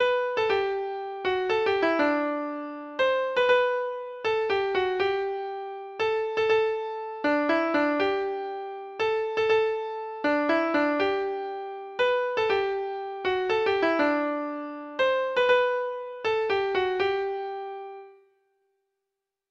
Free Sheet music for Treble Clef Instrument